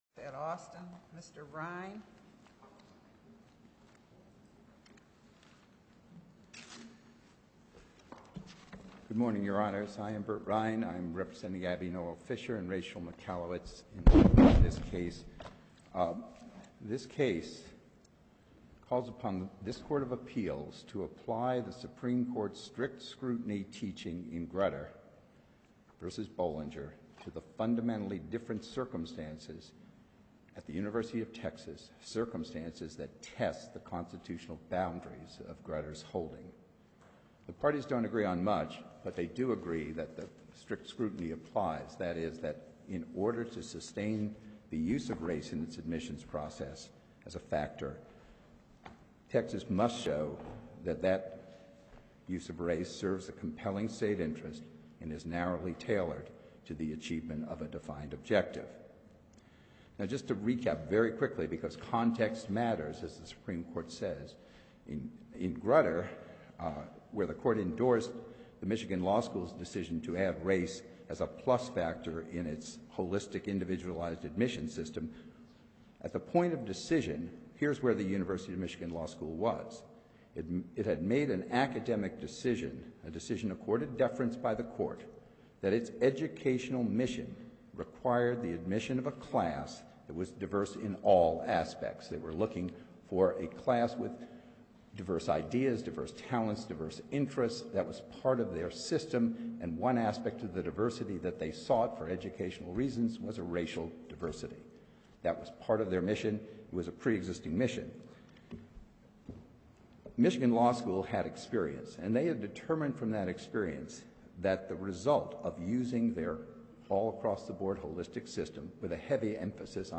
You can access the audio of today’s oral argument before a three-judge panel of the U.S. Court of Appeals for the Fifth Circuit via this link (20.2MB Windows Media audio file).